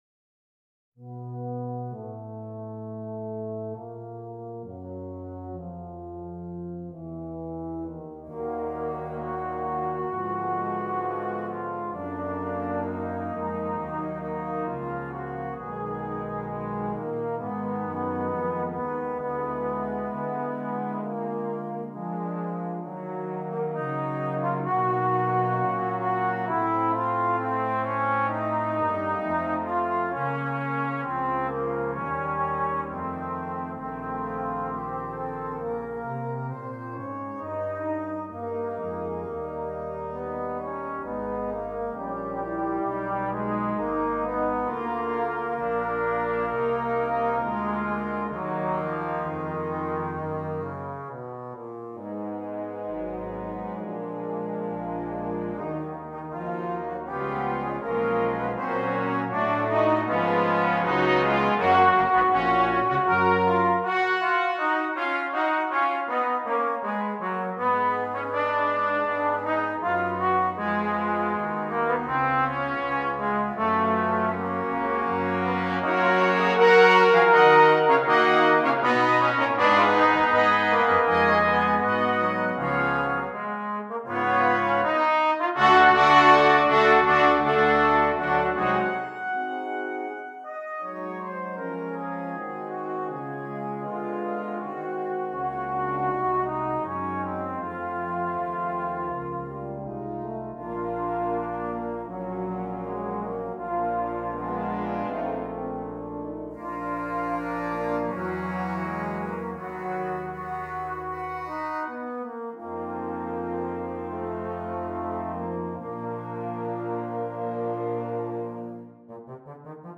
Brass Choir (solo trombone 4.4.2.1.1.perc)